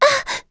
Dosarta-Vox_Damage_01.wav